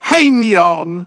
synthetic-wakewords
synthetic-wakewords / hey_neon /ovos-tts-plugin-deepponies_Discord_en.wav
ovos-tts-plugin-deepponies_Discord_en.wav